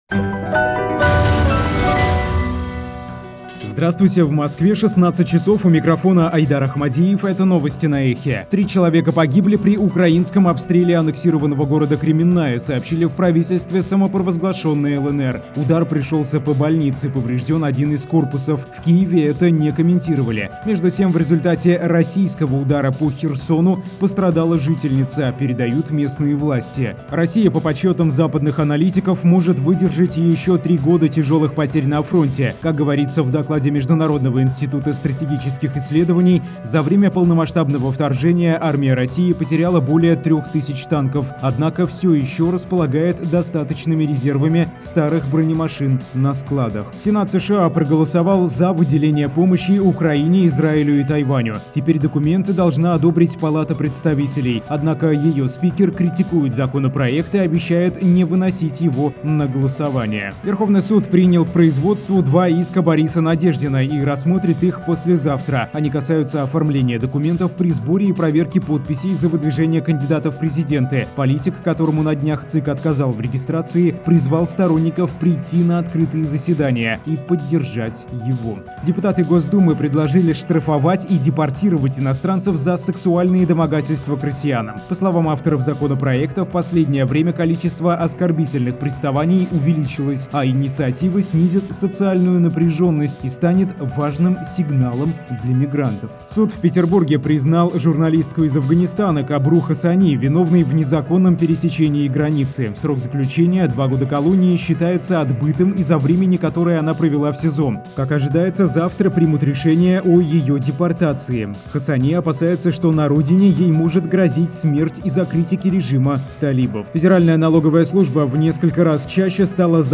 Слушайте свежий выпуск новостей «Эха»